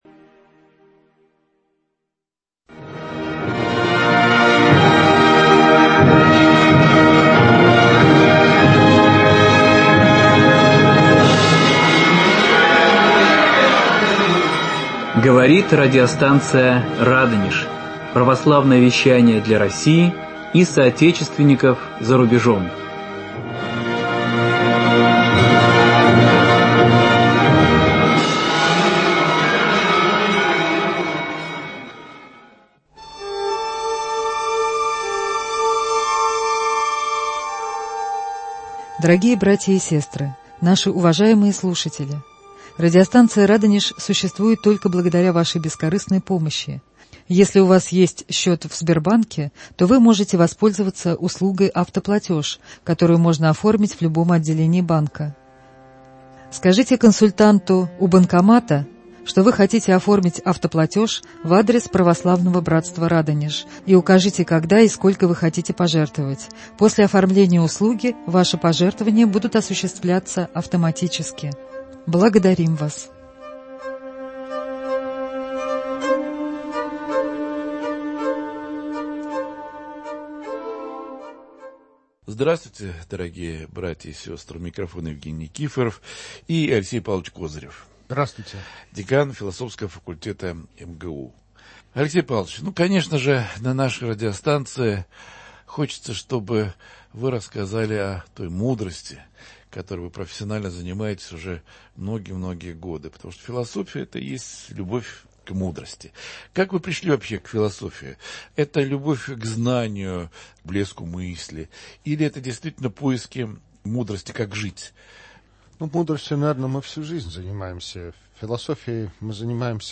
Прямой эфир радио